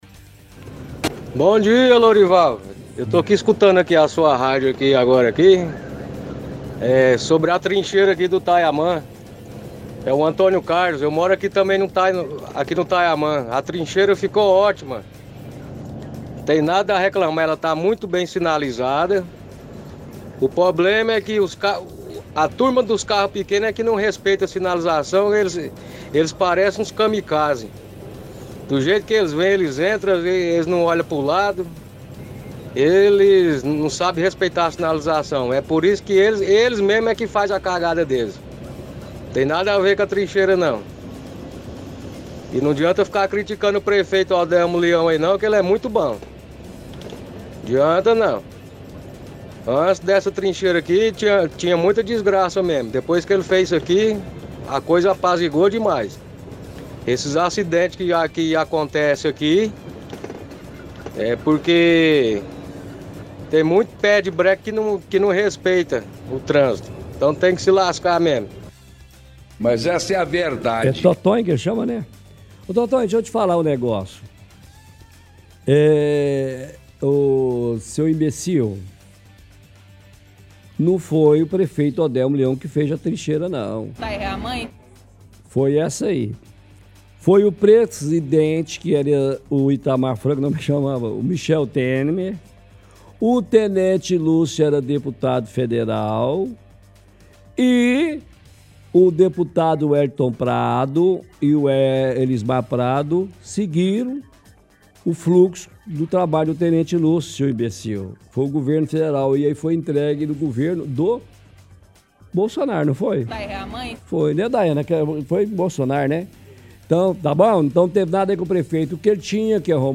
Home / Rádio / Pinga fogo – Trincheira no bairro Taiaman